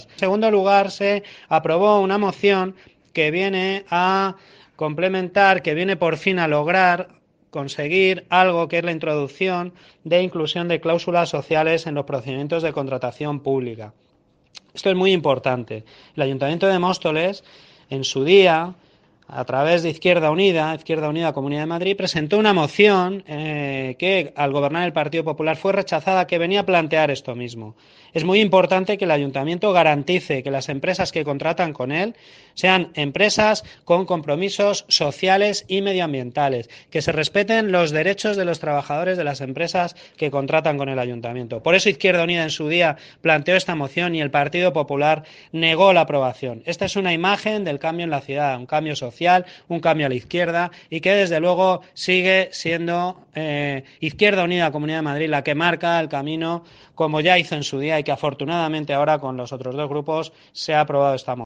Audio - Eduardo Gutierrez (Concejal de Urbanismo y Vivienda) Sobre Cáusulas Sociales